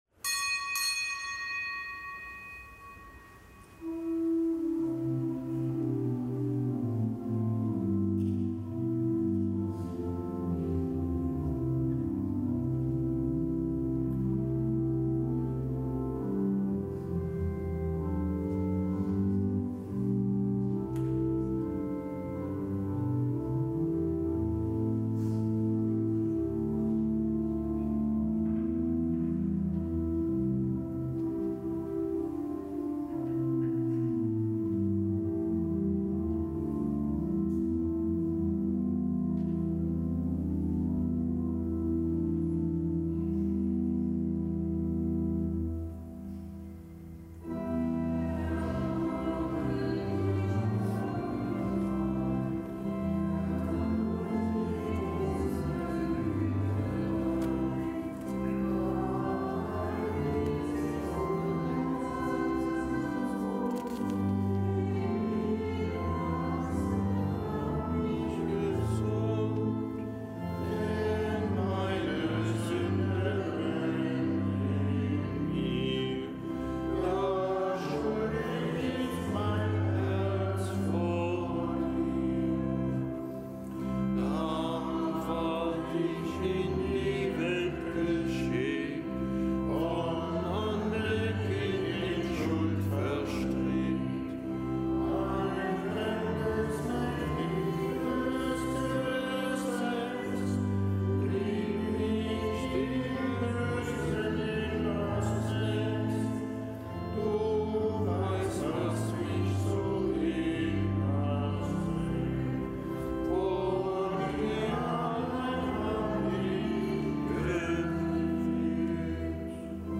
Kapitelsmesse aus dem Kölner Dom am Dienstag der dritten Fastenwoche. Zelebrant: Weihbischof Dominikus Schwaderlapp.